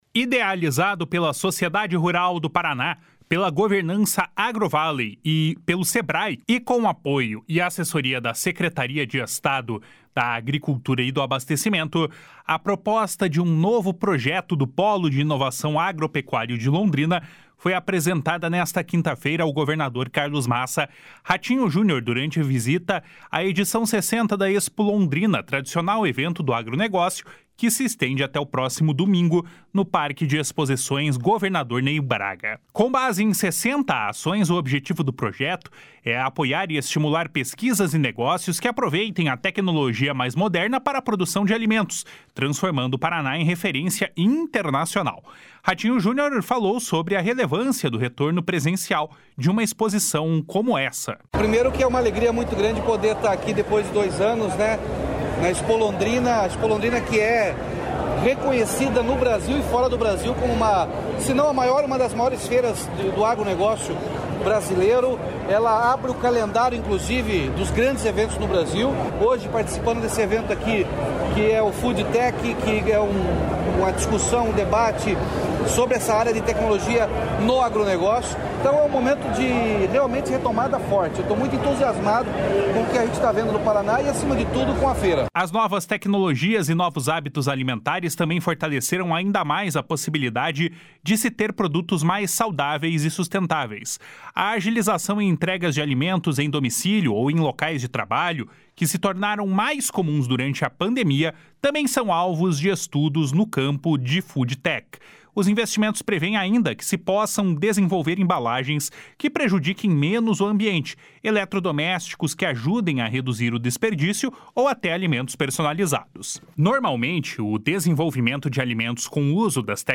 // SONORA RATINHO JUNIOR //
// SONORA NORBERTO ORTIGARA //